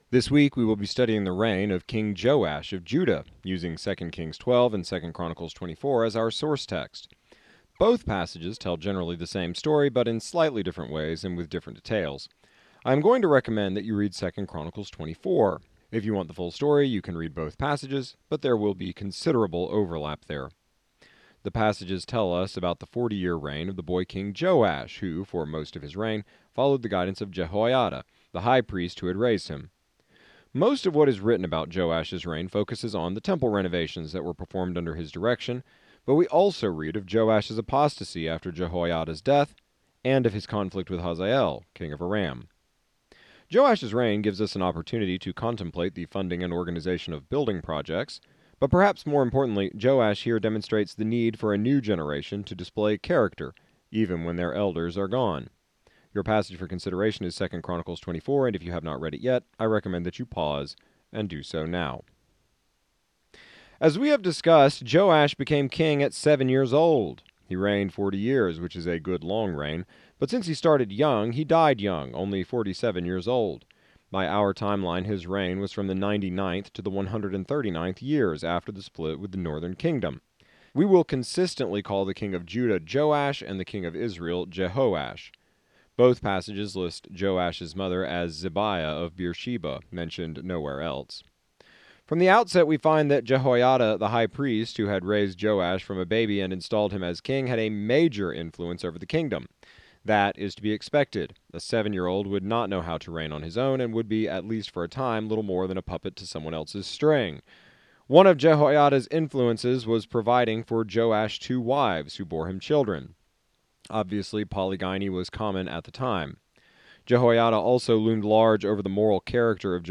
exegetical sermon series through the entire Bible